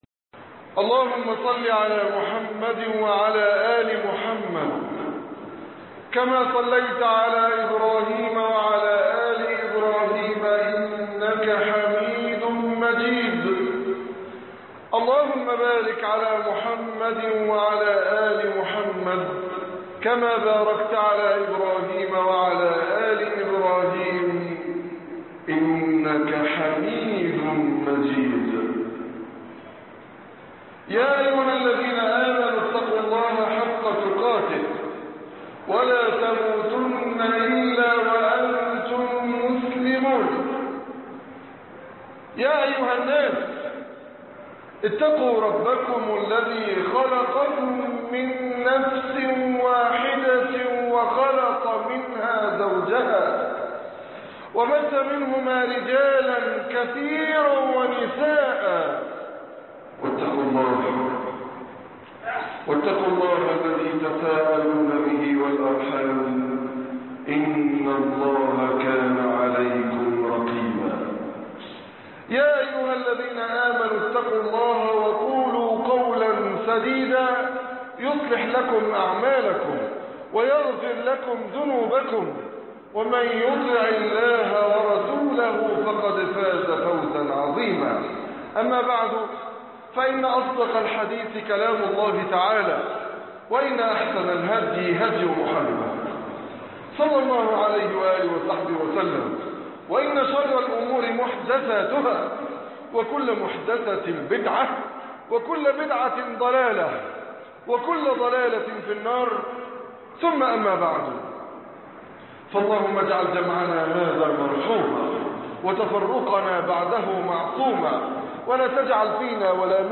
عام جديد ومركب رشيد وتعويم الجنية _ خطب الجمعة